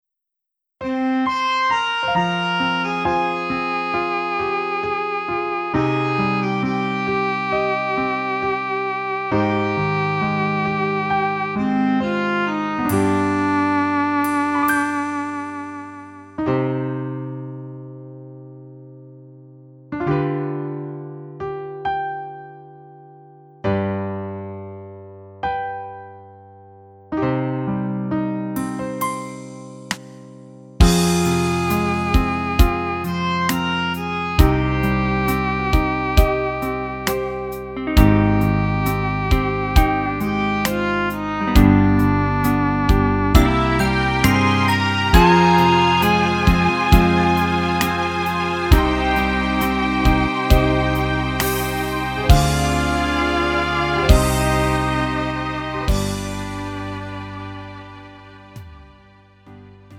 음정 원키 3:47
장르 구분 Lite MR
Lite MR은 저렴한 가격에 간단한 연습이나 취미용으로 활용할 수 있는 가벼운 반주입니다.